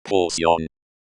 Note that 포션posyeon/
pʰo.sjʌ̹n/ is a direct borrowing of English potion.